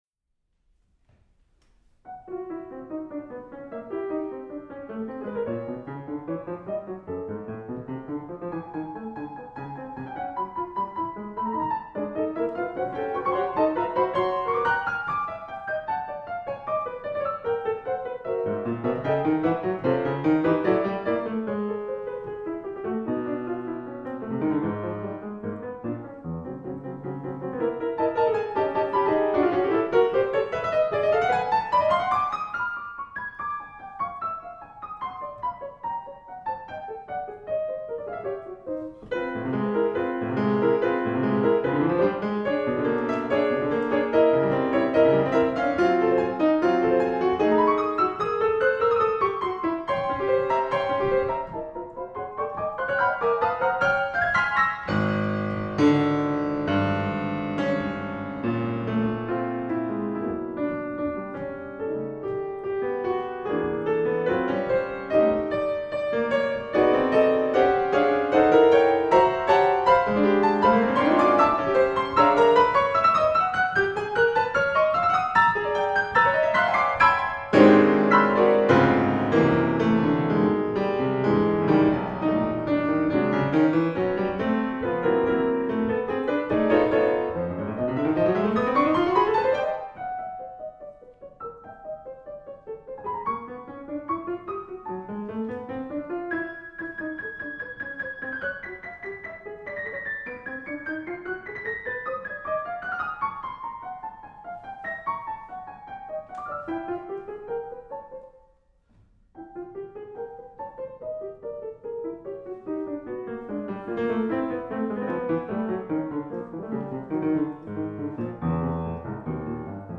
3. Rondo (Con spirito)